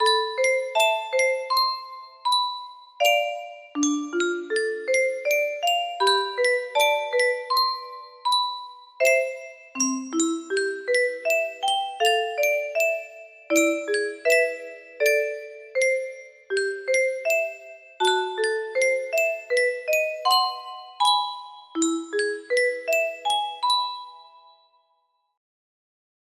Semi-Simple melody
Loopable